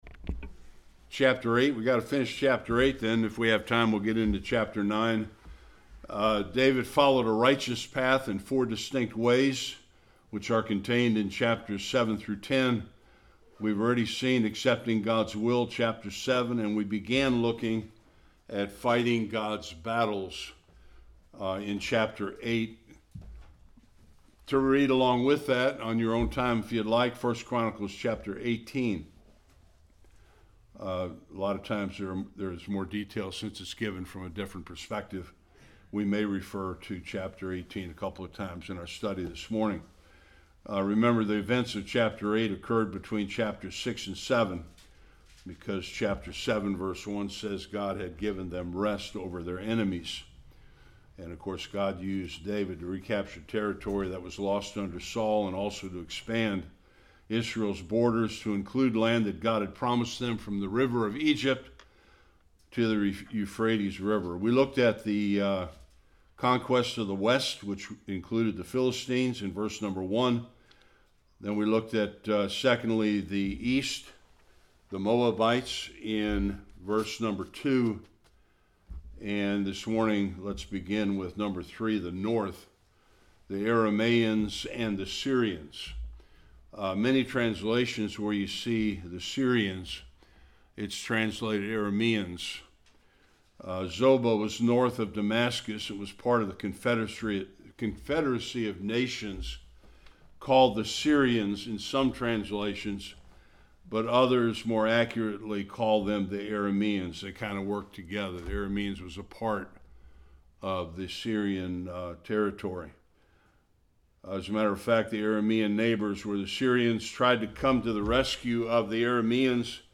1-13 Service Type: Sunday School King David’s victories were extensive.